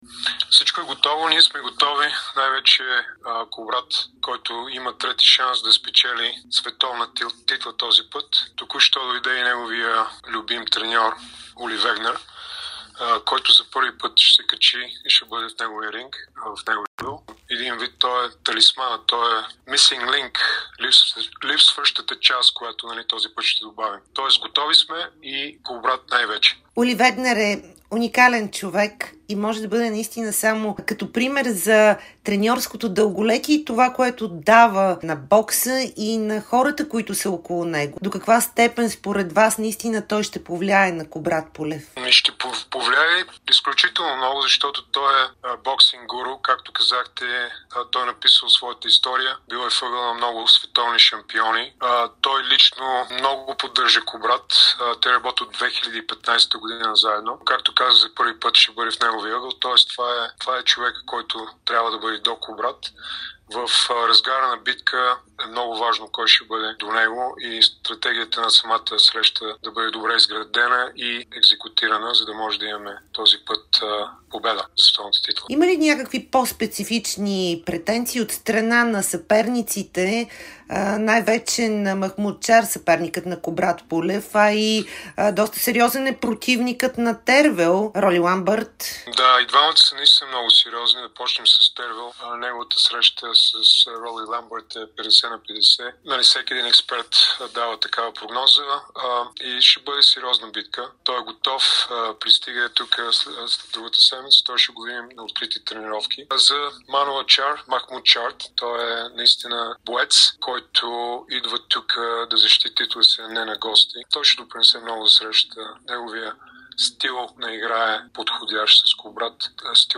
ексклузивно интервю пред Дарик радио